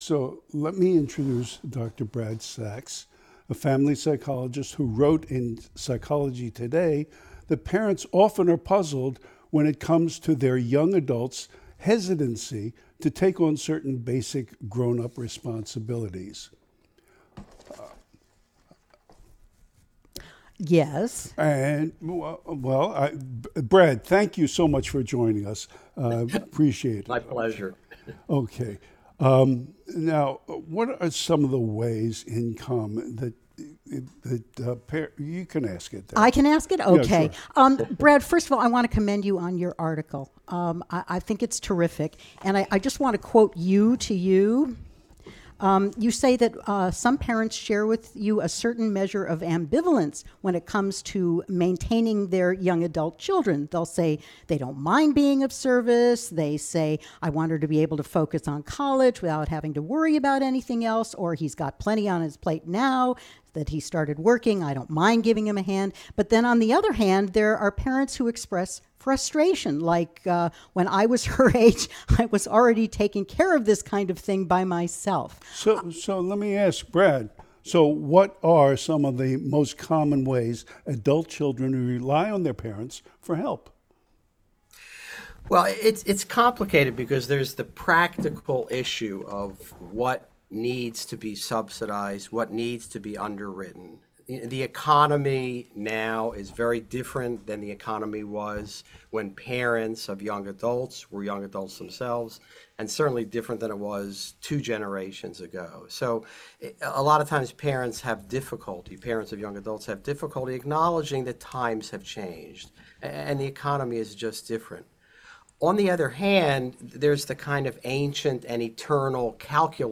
Listen to the interview from The Psychiatry Show on Sirius XM’s “Doctor Radio,”